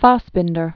(fäsbĭndər), Rainer Werner 1945-1982.